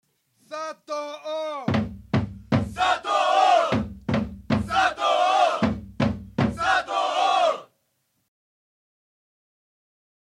選手のコール
基本は「選手苗字＋（ドンドンドン）」（太鼓に合わせて手拍子）。